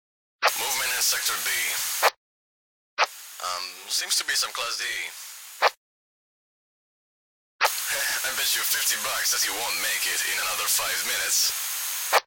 scpcb-godot/SFX/Radio/Chatter4.ogg at bef423c5a75070d9c227c54f0fcb9b17fa33683c
Chatter4.ogg